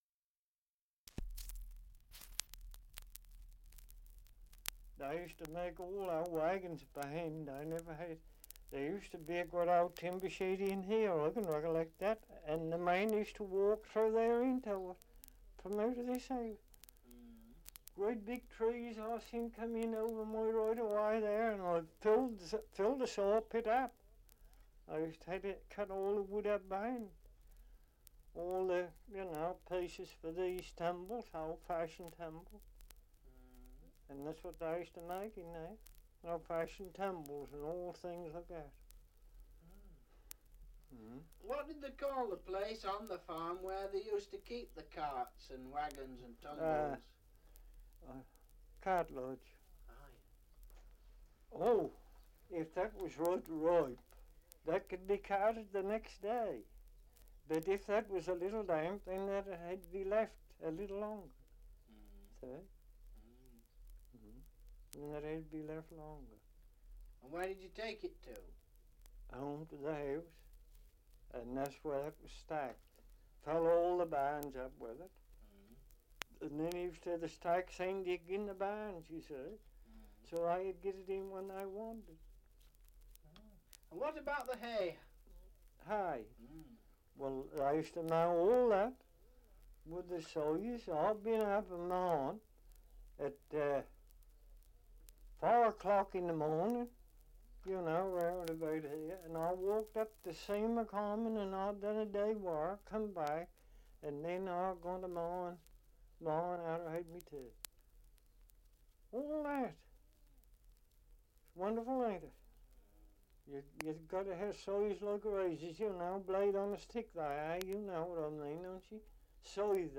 2 - Survey of English Dialects recording in Kersey, Suffolk
78 r.p.m., cellulose nitrate on aluminium